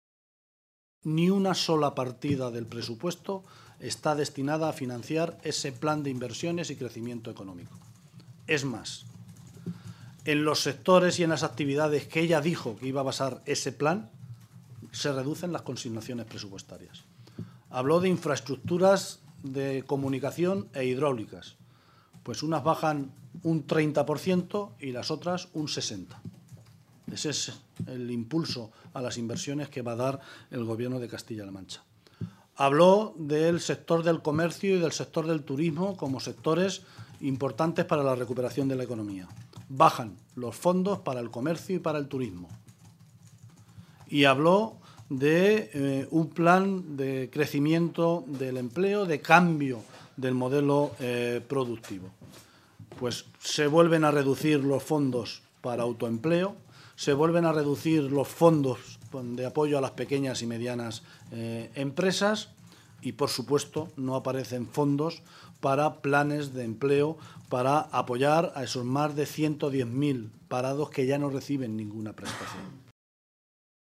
Guijarro realizó estas declaraciones en una rueda de prensa conjunta con los responsables sindicales de CC OO y UGT